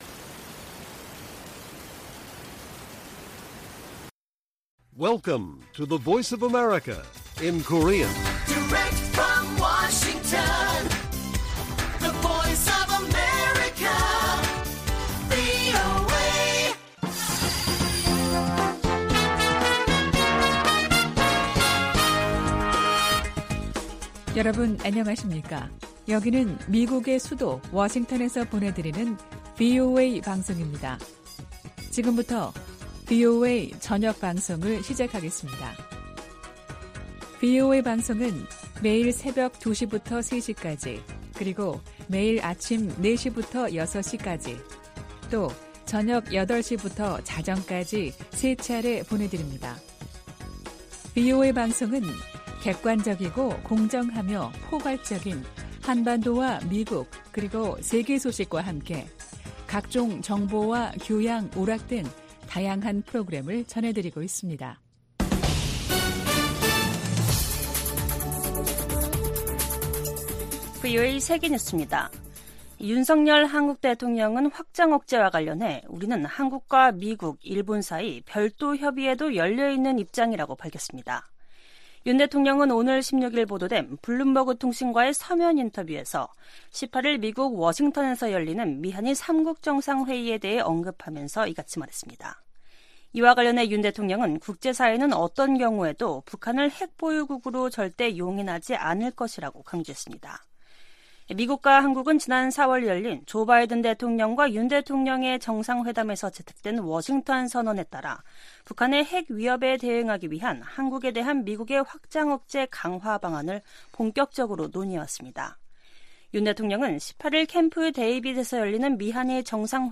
VOA 한국어 간판 뉴스 프로그램 '뉴스 투데이', 2023년 8월 16일 1부 방송입니다. 토니 블링컨 미 국무장관이 오는 미한일 정상회의와 관련해 3국 협력의 중요성을 강조했습니다. 윤석열 한국 대통령은 미한일 정상회의를 앞두고 확장억제와 관련해 미한일 사이 별도의 협의도 열려 있다고 밝혔습니다. 미 국방부는 최근 김정은 북한 국무위원장이 ‘전쟁 준비 태세를 갖추라’고 지시한 것과 관련해 한국, 일본에 대한 미국의 안보 공약은 분명하다고 강조했습니다.